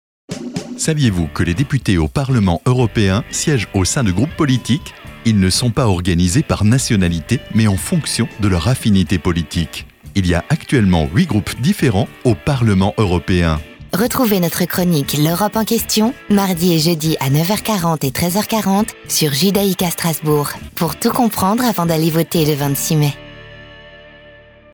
Nous vous proposons de retrouver sur cette page 10 chroniques radio et 5 spots “le saviez-vous ?” sur les élections européennes diffusés au cours des mois de février et mars sur deux radios partenaires, AzurFm et Radio Judaïca Strasbourg.
les spots radios : le saviez-vous ?